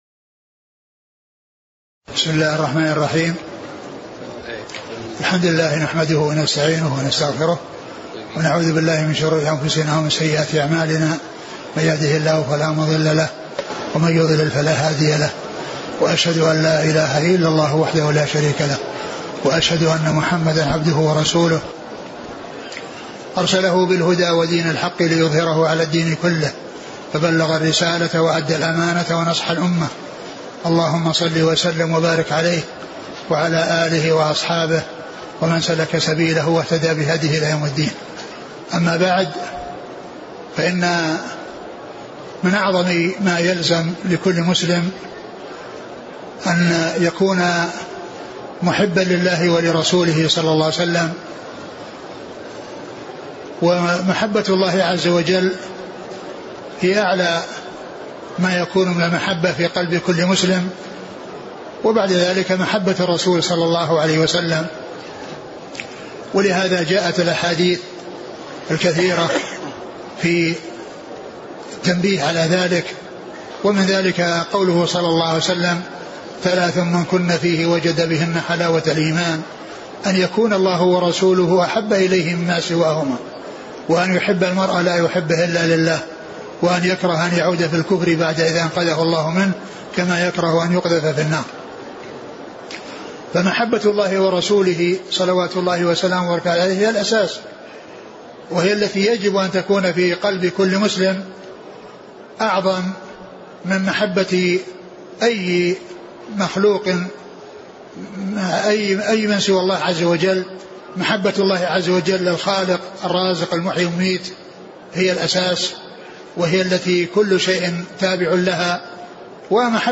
محاضرة - بدعة المولد النبوي ومحبة النبي ﷺ